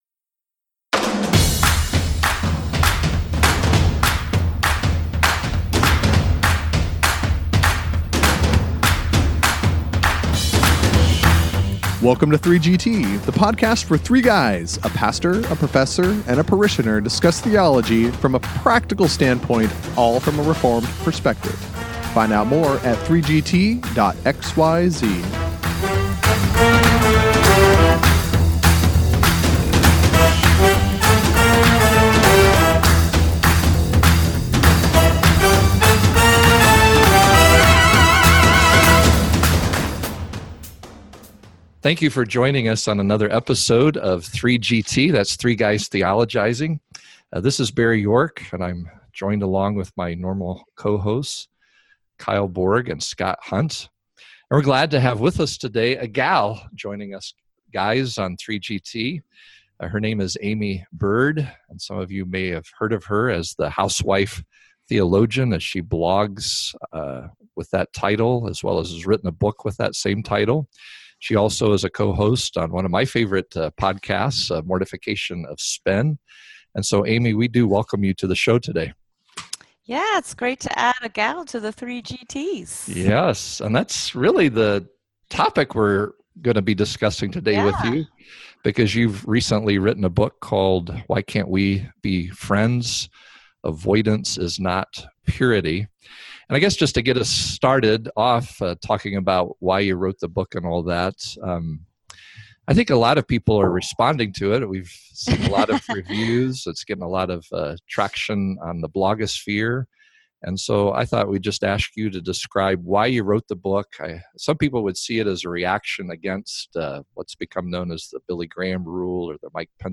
Yes, a gal joins the guys on this latest episode!